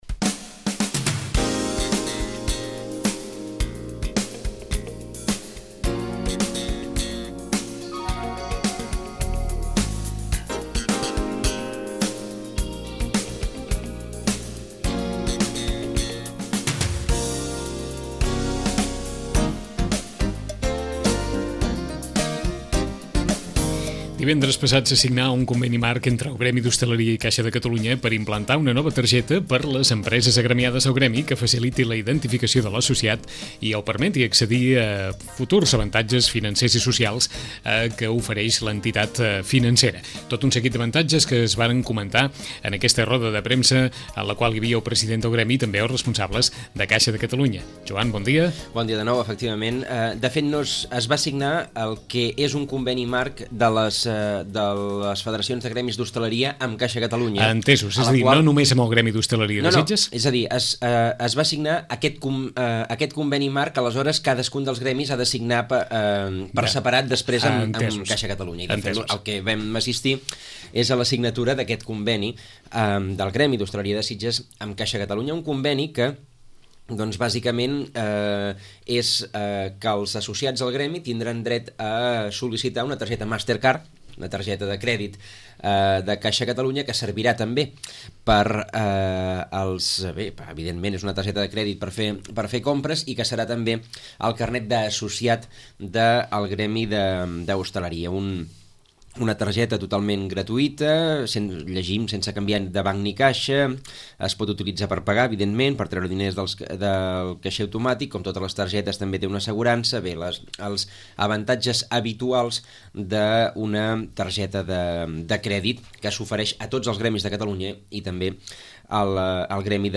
En Roda de premsa